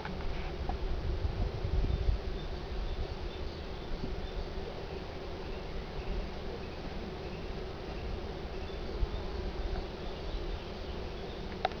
Hummeln und Bienen im blühenden Lindenbaum
Sehr leise aber doch charakteristisch für die Lindenblüte sind die Flügelschläge der Insekten wahrzunehmen.
Abb. 02: Der leichte Summton ist charakteristisch für die Blütezeit.
Abb. 03: neben Vogelgesang im Bereich zwischen 2500 und 4000 Hz gibt es bei rund 400 Hz ein durchgehendes Band, das von den Flügelschlägen der Insekten herrührt.